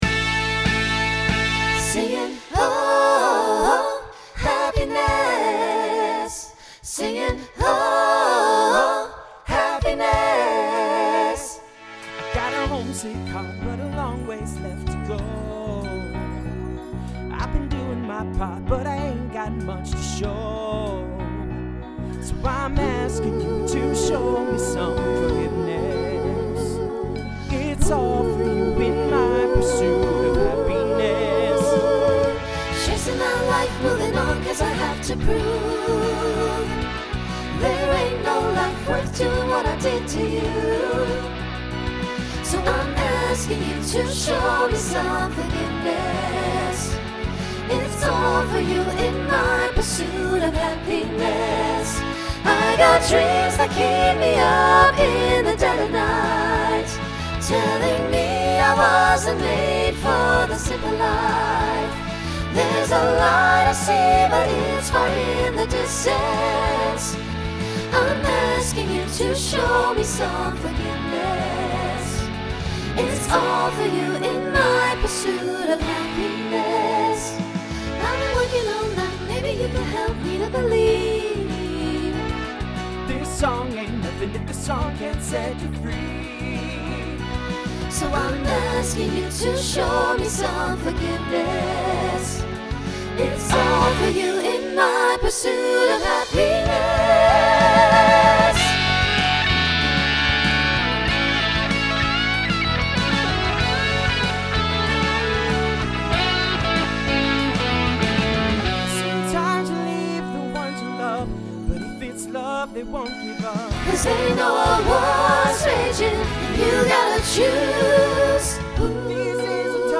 Genre Rock Instrumental combo
Mid-tempo , Transition Voicing SATB